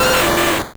Cri de Magmar dans Pokémon Or et Argent.